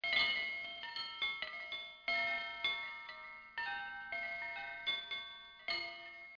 wndchm04.mp3